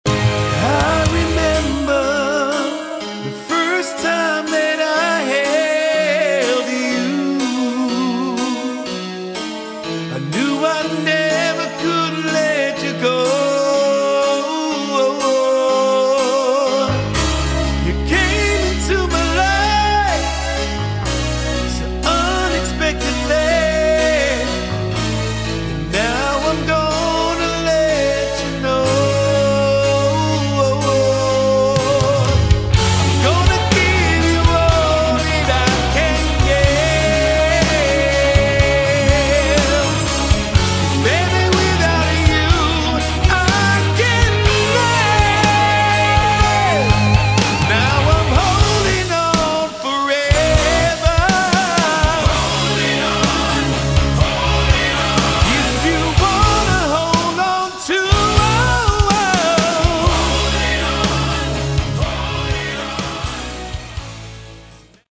Category: Hard Rock
Vocals, Keys
Drums
Bass
Guitars